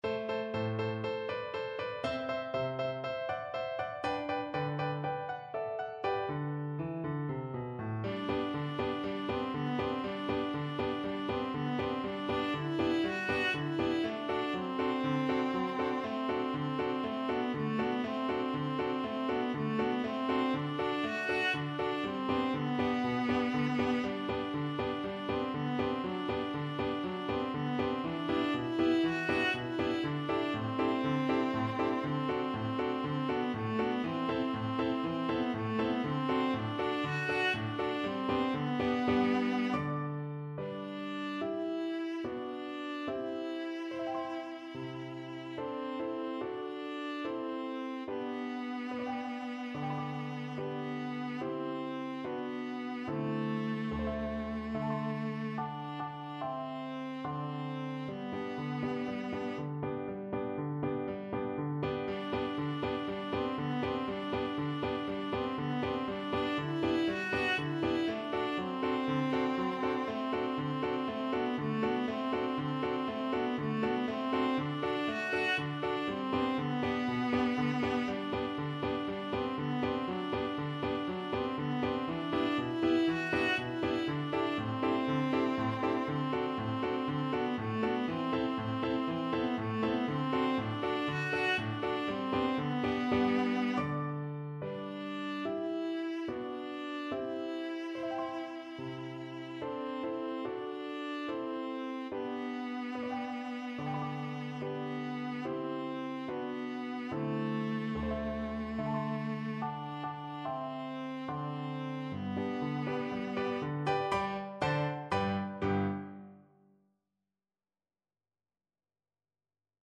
G major (Sounding Pitch) (View more G major Music for Viola )
4/4 (View more 4/4 Music)
Allegro (View more music marked Allegro)
Viola  (View more Easy Viola Music)
Classical (View more Classical Viola Music)